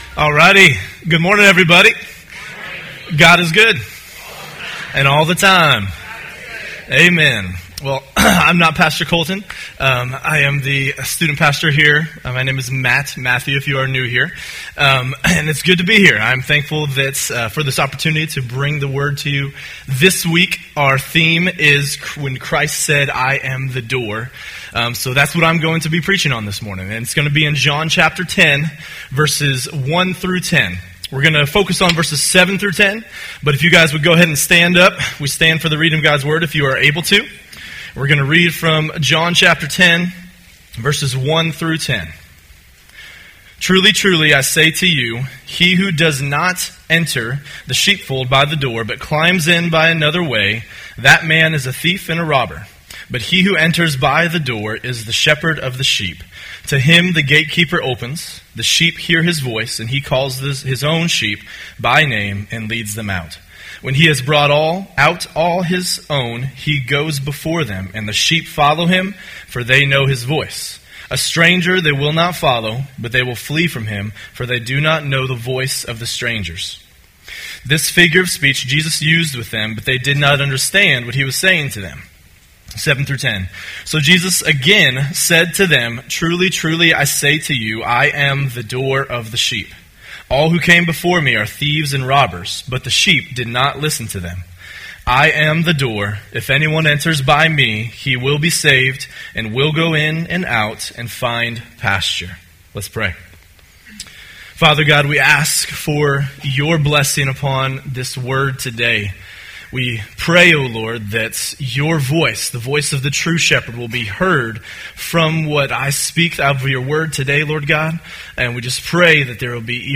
Sermons | Christian Covenant Fellowship